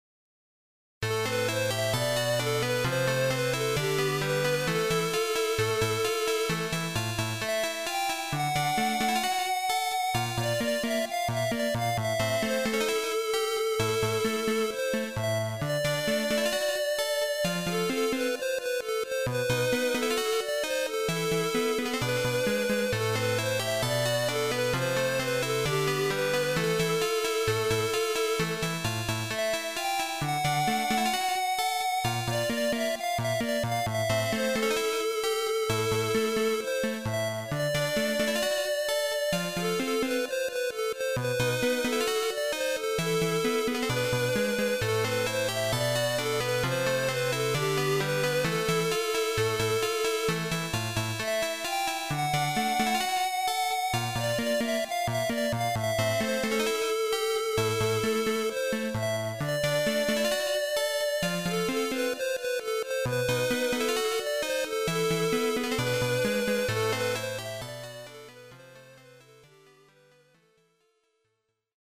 ここではMUCOM88winで作ったMUCソースとMP3化したファイルを掲載しています。